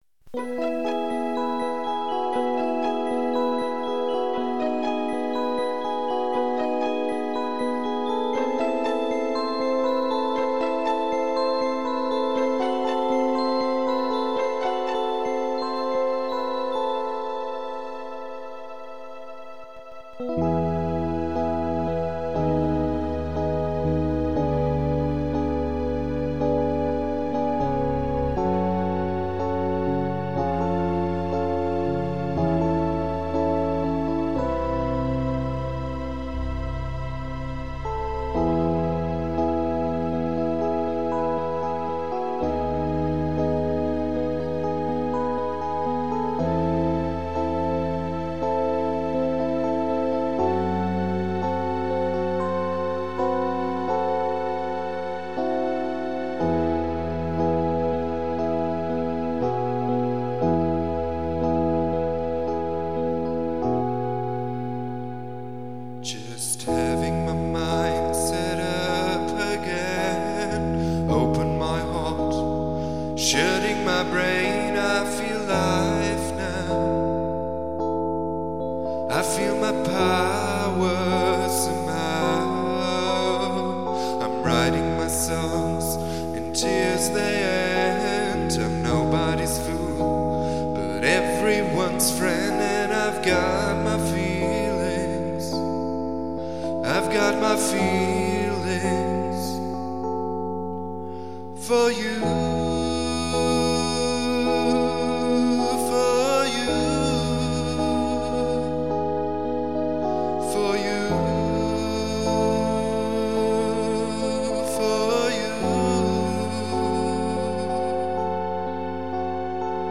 Beginnt als schöne Ballade, wandelt sich dann aber im zweiten Teil zum Prog-Bombast.